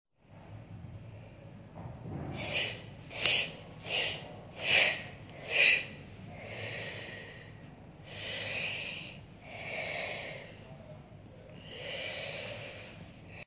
a field recording of the sounds of the dancers and dance classes performing on location at Dance City